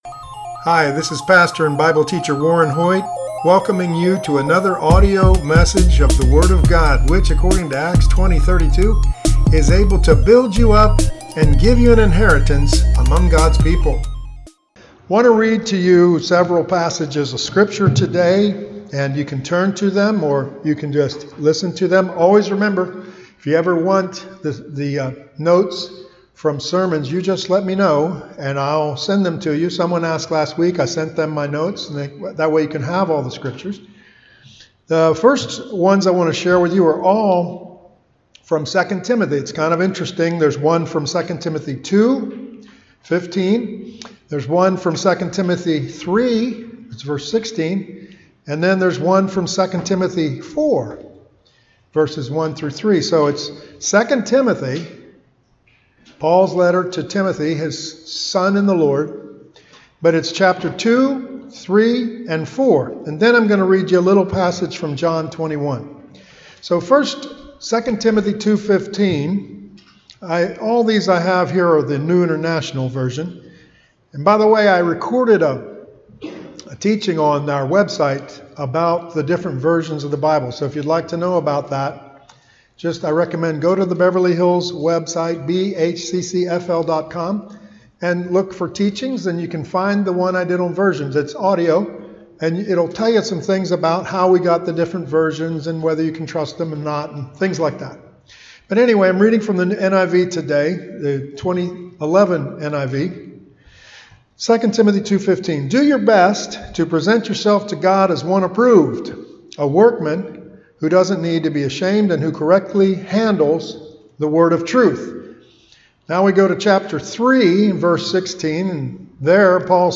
English Sermons